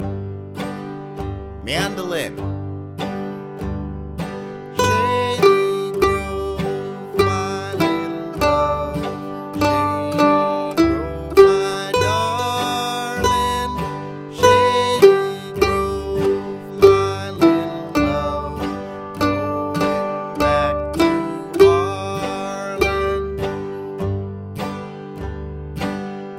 Picking: Guitar